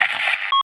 pda_welcome.ogg